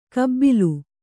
♪ kabbilu